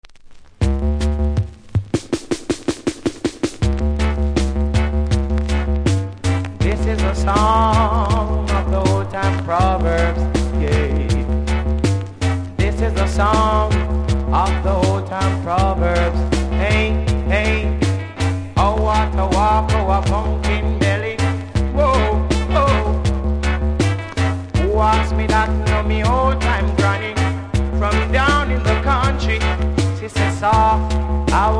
REGGAE 80'S
多少ノイズありますので試聴で確認下さい。